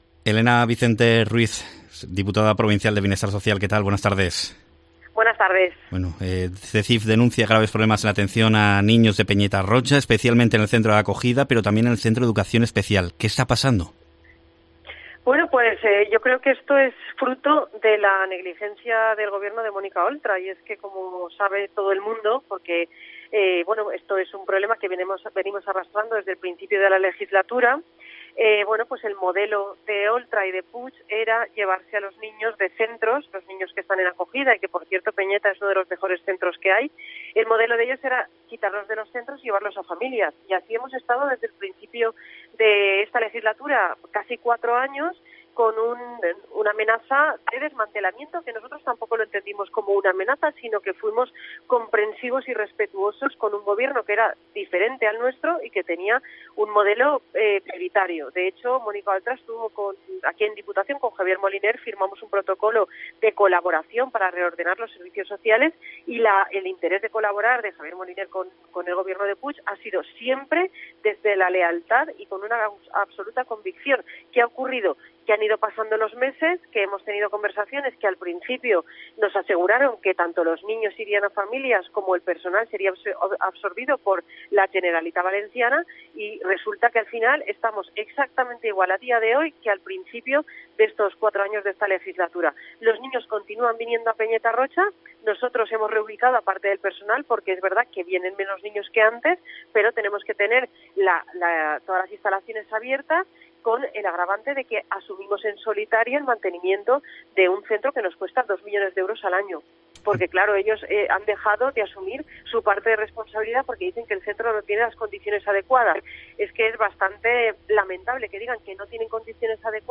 Responsabiliza en declaraciones a COPE, la diputada provincial, a Ximo Puig y a Mónica Oltra, ya que le dijeron que no valía la pena invertir en Peneya Roja.